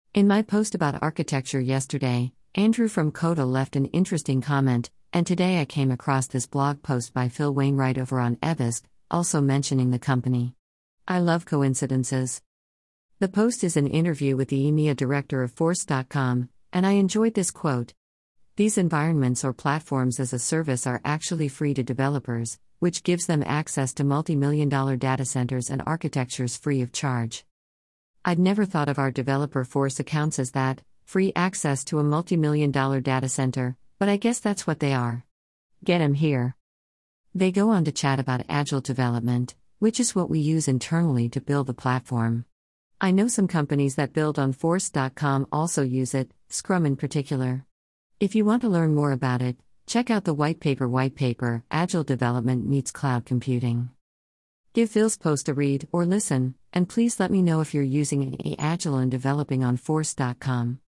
Interview about Agile Development in the Cloud